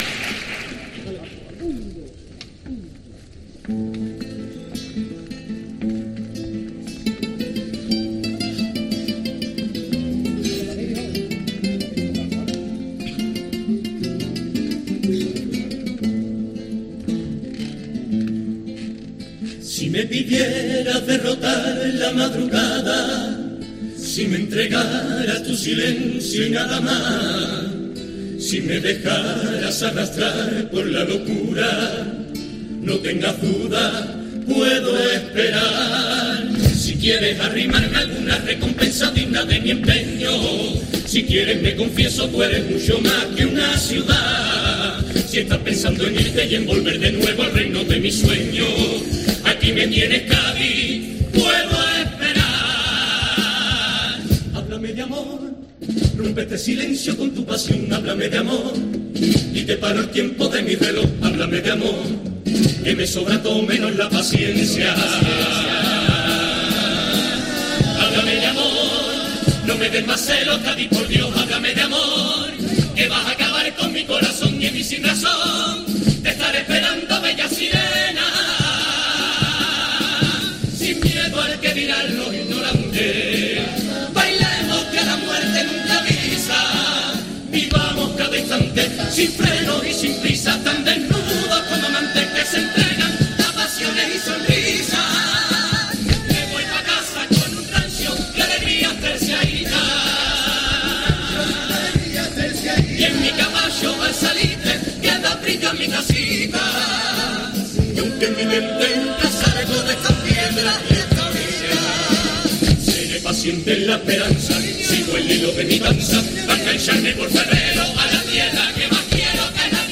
AUDIO: Escucha esta selección de cuartetas de agrupaciones como 'Los pacientes', 'Los listos', 'Chernobyl el musical', 'Los resilientes', 'La chusma selecta'...